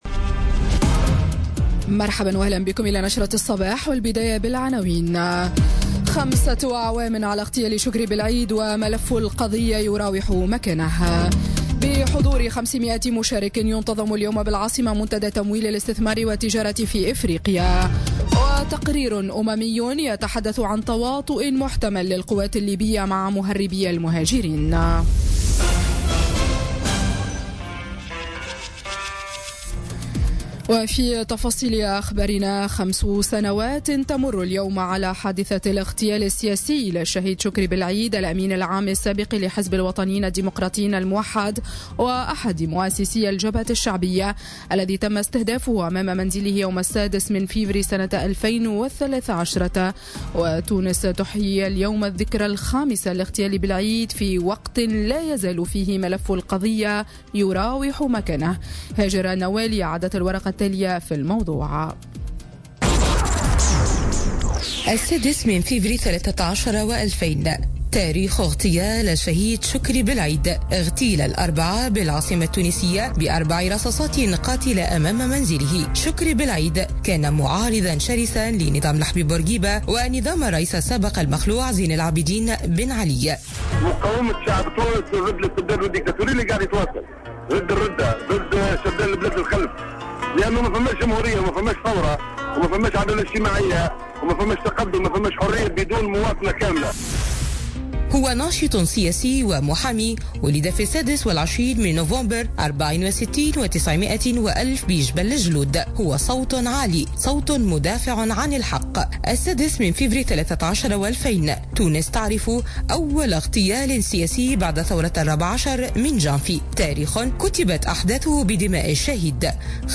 نشرة أخبار السابعة صباحا ليوم الثلاثاء 6 فيفري 2018